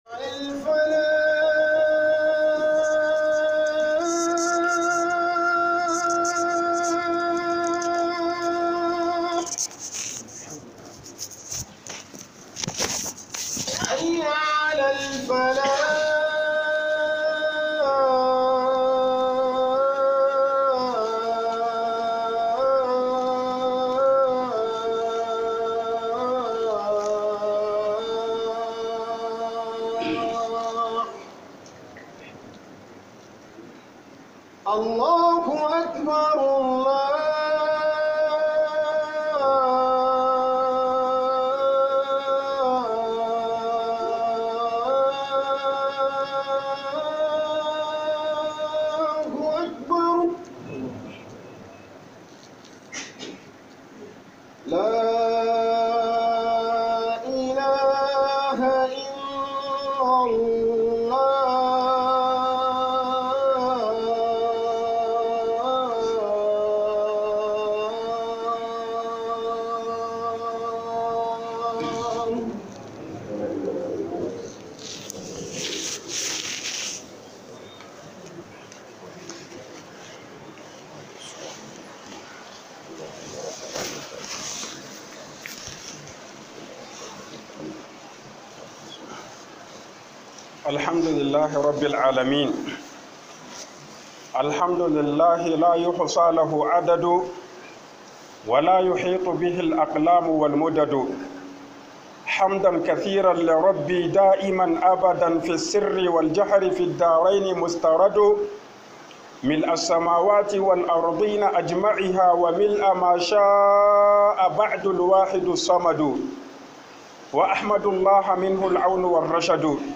huduba (2) - HUƊUBOBIN JUMA'A
huduba (2)